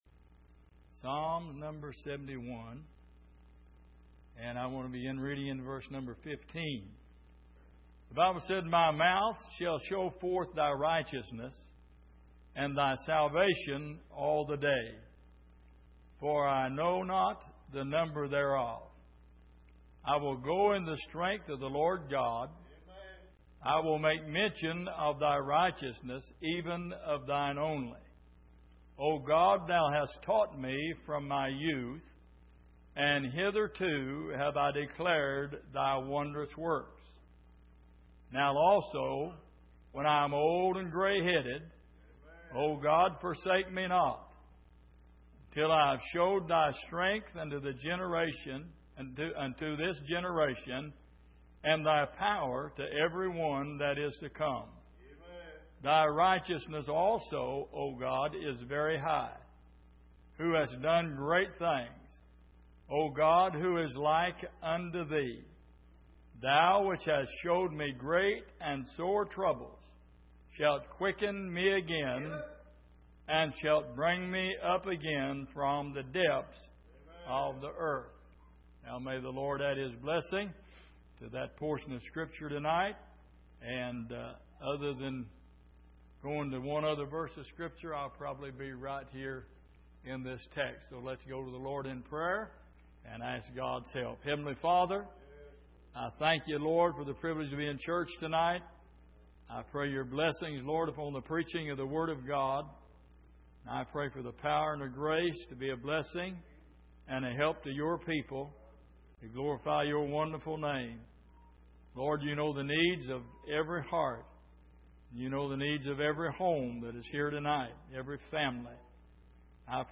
Exposition of the Psalms Passage: Psalm 71:15-20 Service: Midweek Some Things That We Need To See « Why Are You Here?